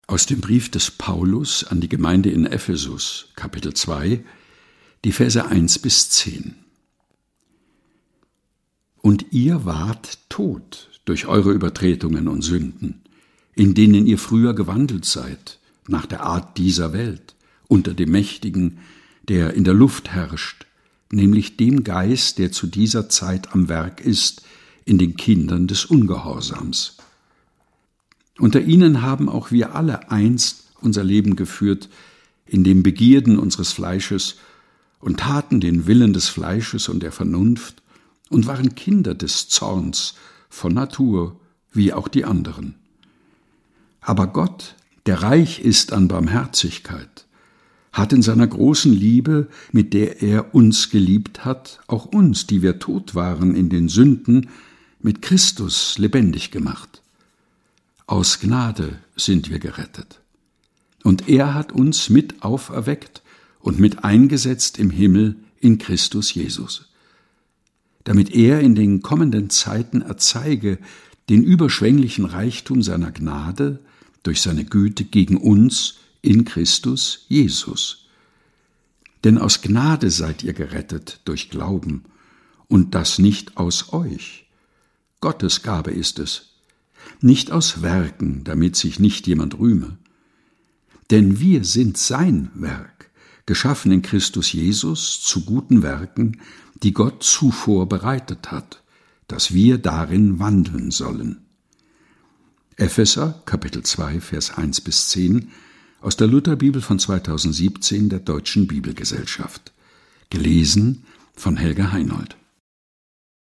liest